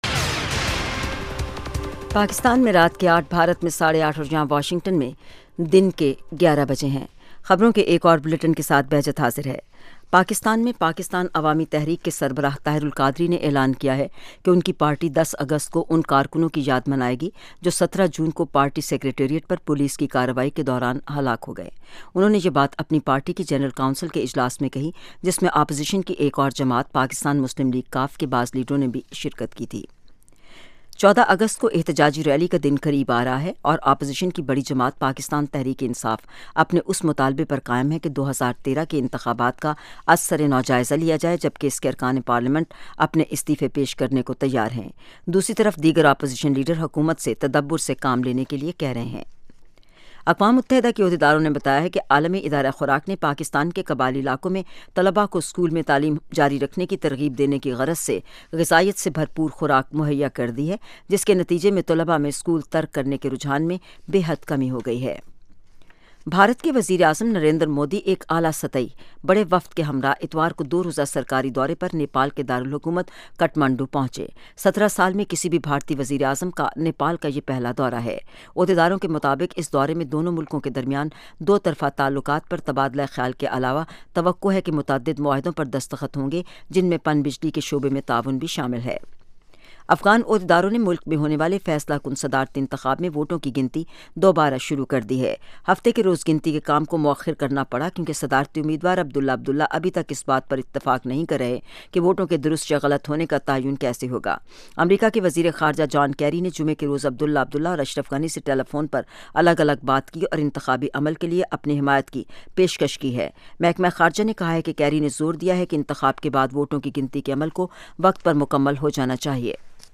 In The News: 8:00PM PST ایک گھنٹے دورانیے کے اس پروگرام میں خبروں کے علاوہ مہمان تجزیہ کار دن کی اہم خبروں کا تفصیل سے جائزہ لیتے ہیں اور ساتھ ہی ساتھ سننے والوں کے تبصرے اور تاثرات بذریعہ ٹیلی فون پیش کیے جاتے ہیں۔